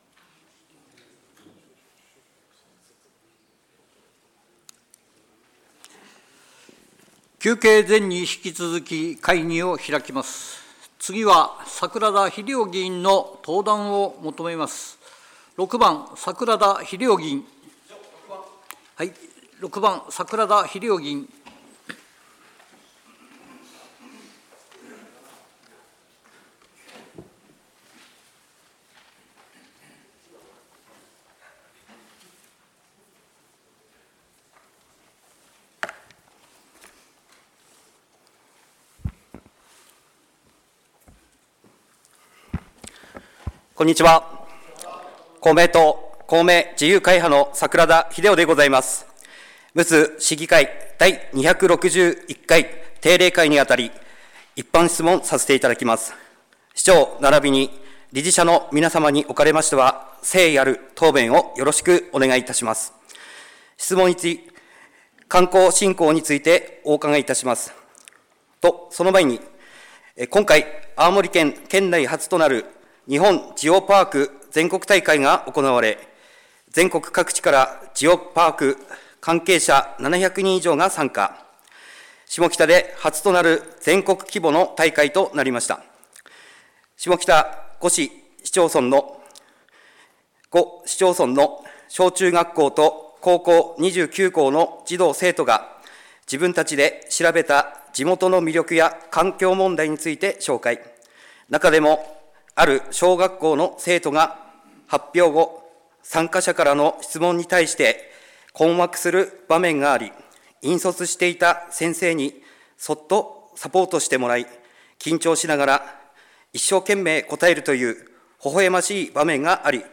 むつ市議会では、本会議のようすを多くの皆さんに聴いていただくために、音声データを掲載しています。